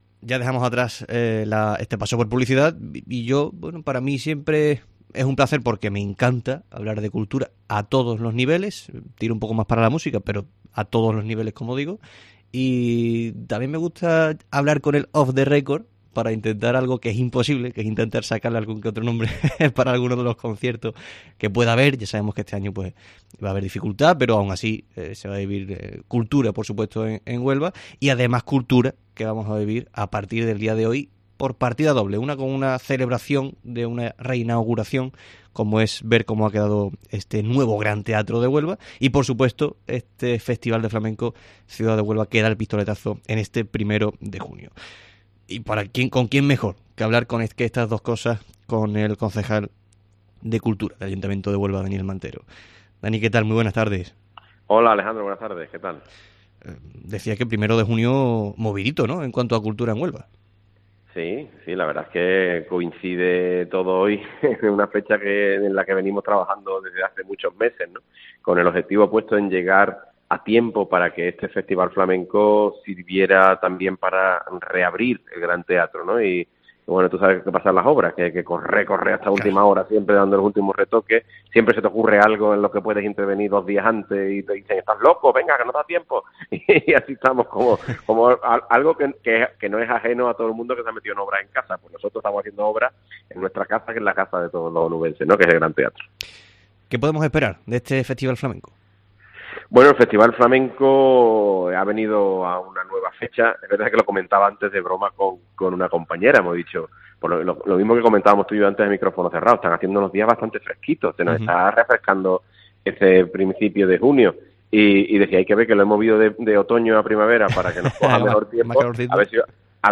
Huelva acoge desde este martes 1 de junio y hasta el próximo domingo 6 de junio el Festival Flamenco 'Ciudad de Huelva' que hemos abordado con Daniel Mantero, concejal de Cultura.